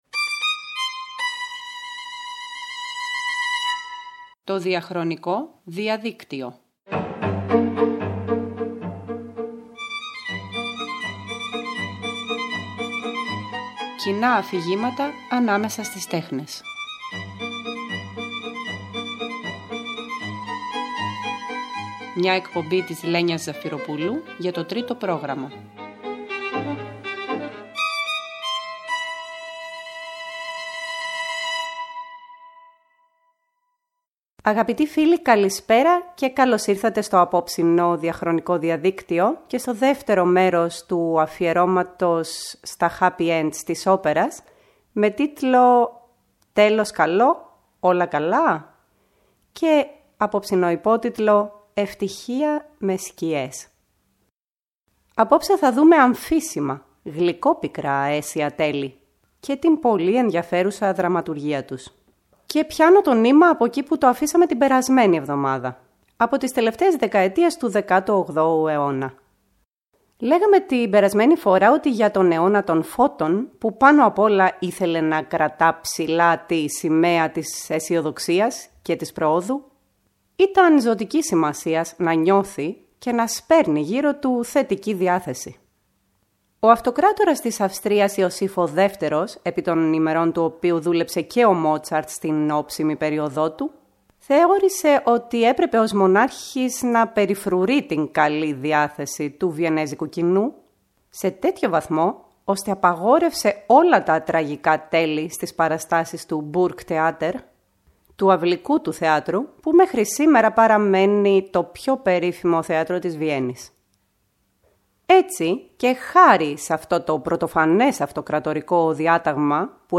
Εκπομπή που αναζητά συνδετικά νήματα ανάμεσα στις εποχές και τα έργα .